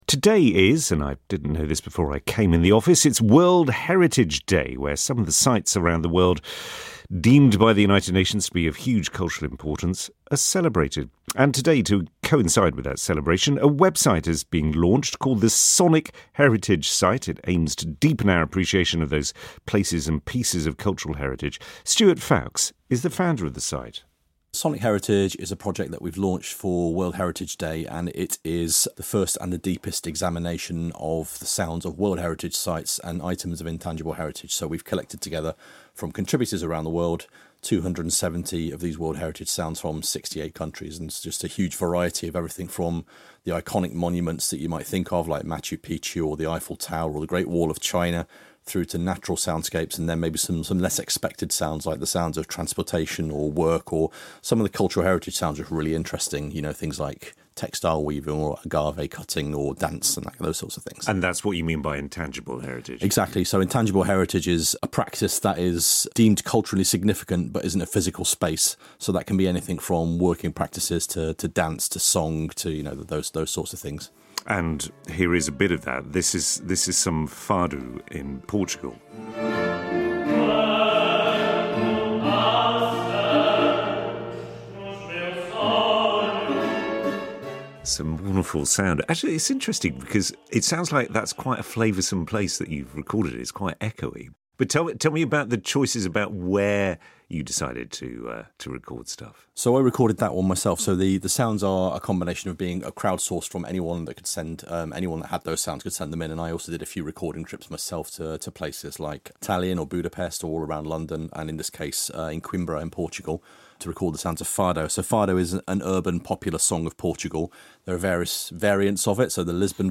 Features sound clips from the Sistine Chapel, Coimbra in Portugal and Okarito in New Zealand.